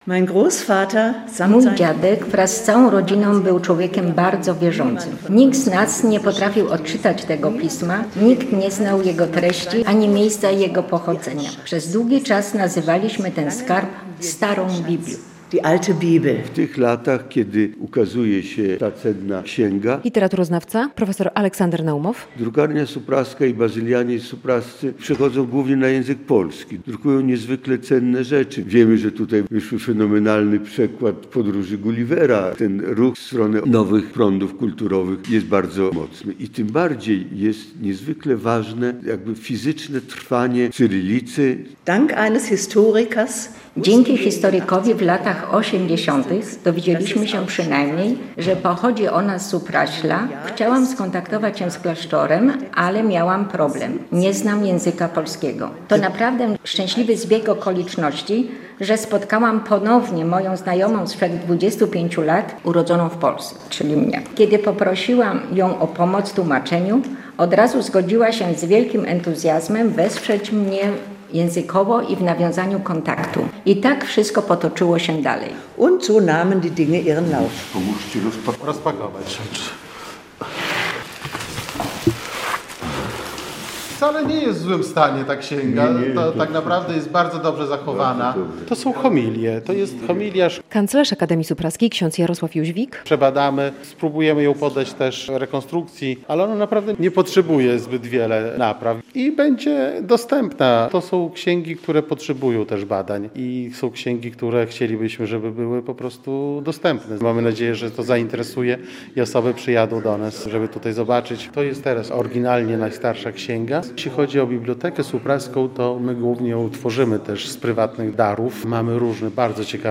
XVIII-wieczna księga wróciła do Supraśla - relacja